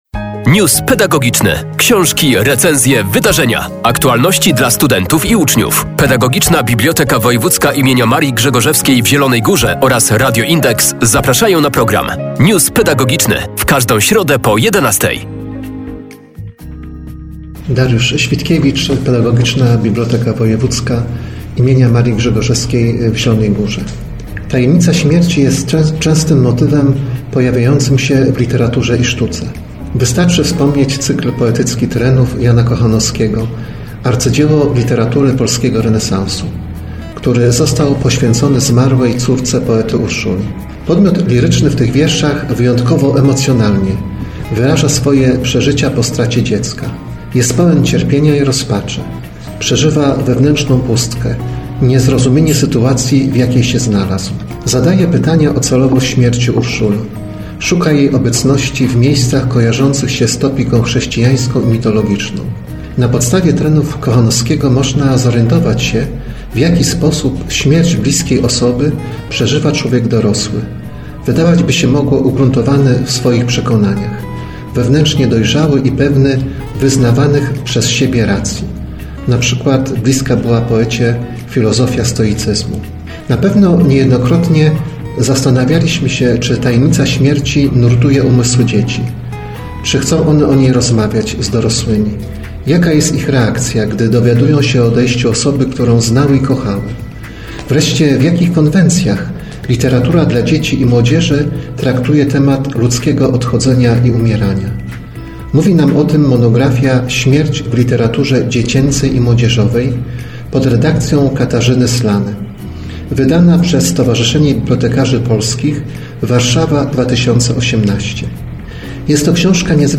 Książka poświęcona została przemijaniu jako jednemu z kluczowych tematów egzystencjalnych obecnych w literaturze kierowanej do niedorosłych odbiorców. News pedagogiczny.